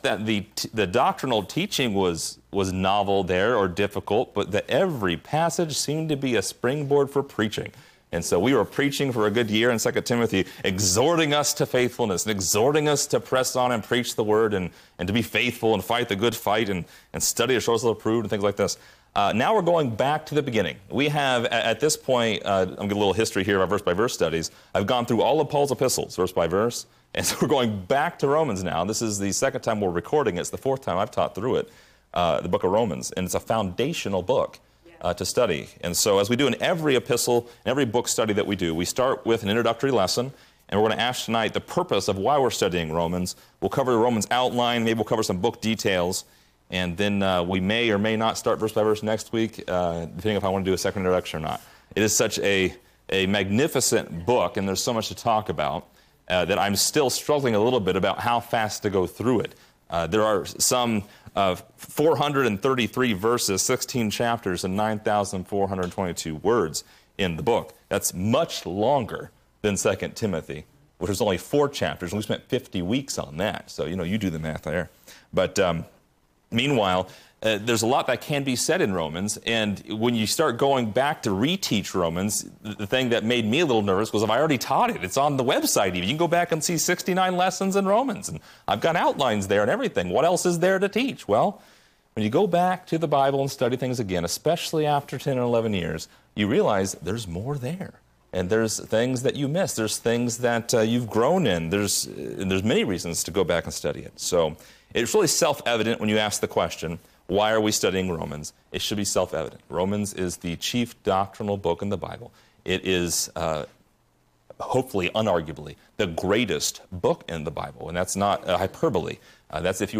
Description: This lesson is part 1 in a verse by verse study through Romans titled: Introduction.